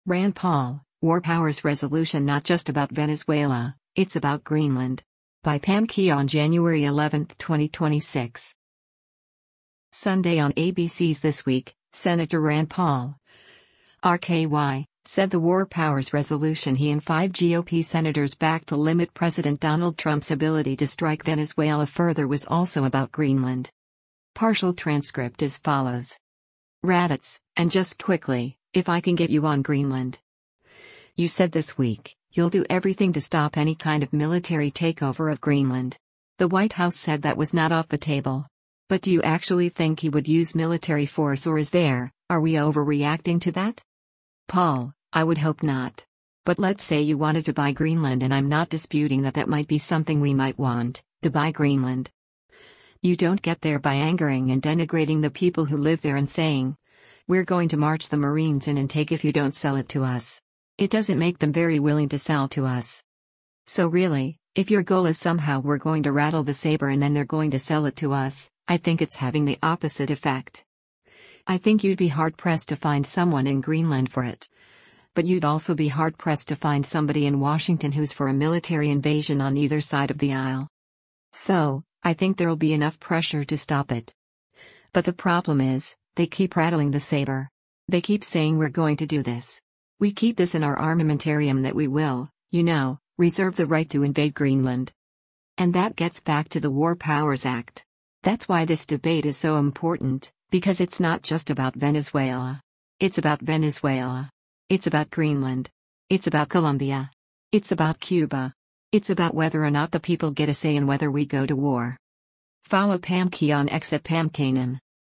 Sunday on ABC’s “This Week,” Sen. Rand Paul (R-KY) said the war powers resolution he and five GOP senators backed to limit President Donald Trump’s ability to strike Venezuela further was also about Greenland.